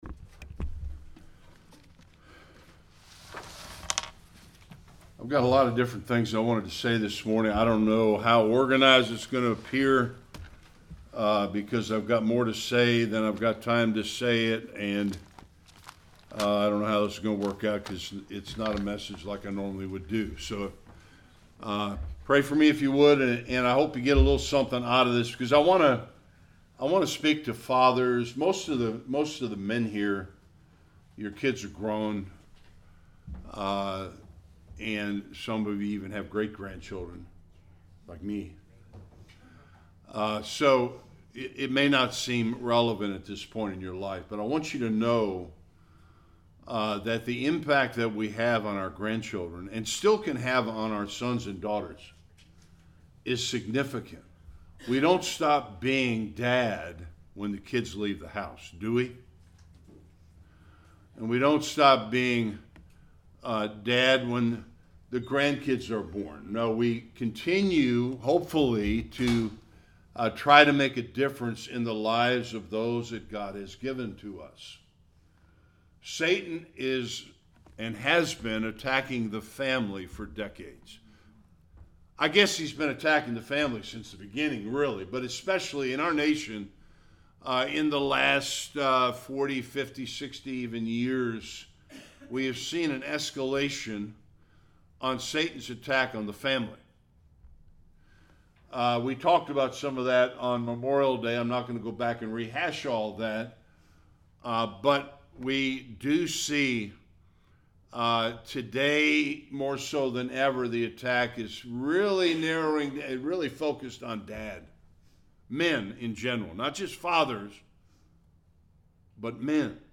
Various Passages Service Type: Sunday Worship Men are under attack in our society.